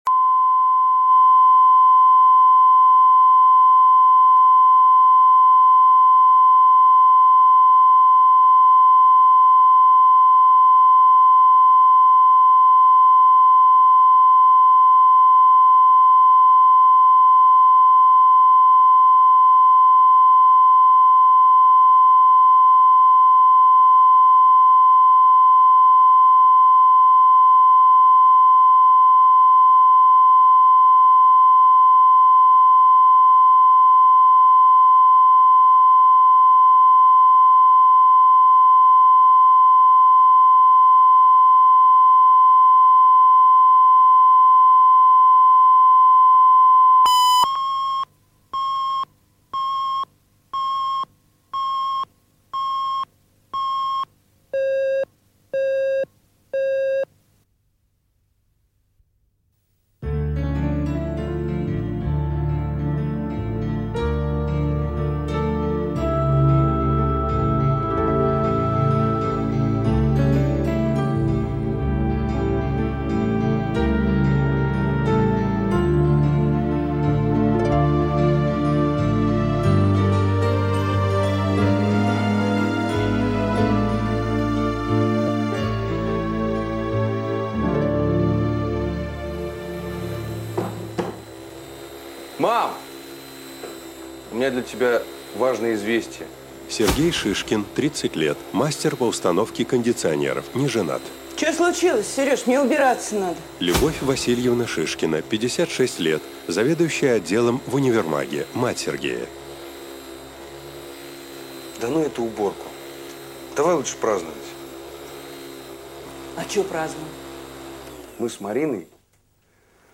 Аудиокнига Сердцу не прикажешь | Библиотека аудиокниг